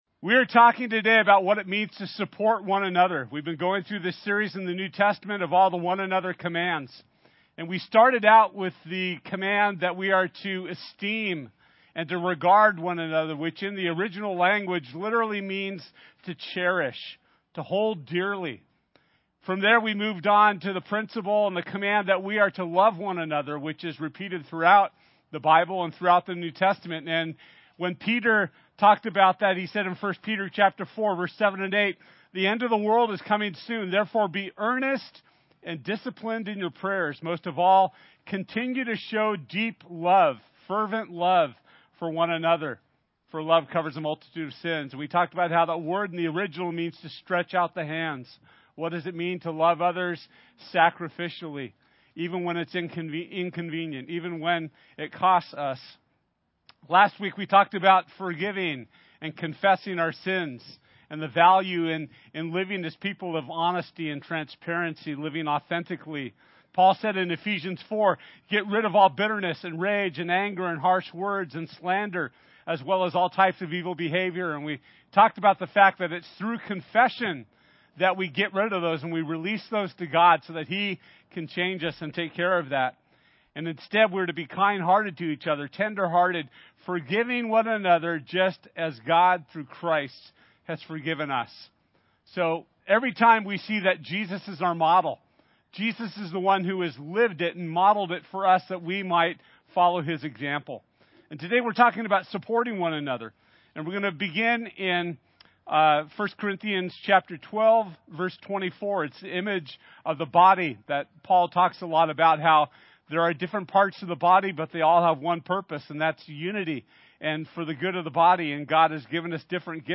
One Another Service Type: Sunday This week we’ll be talking about Supporting One Another as we continue in our One Another Teaching Series.